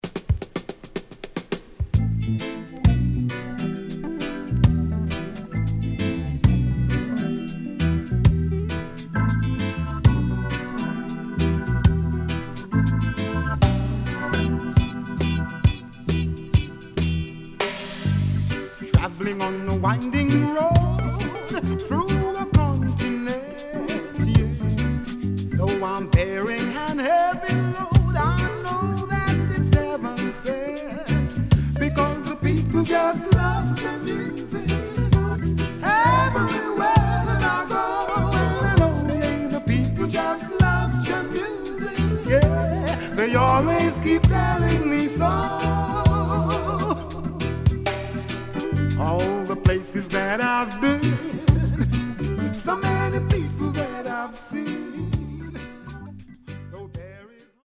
SIDE B:軽いヒスノイズ入りますが良好です。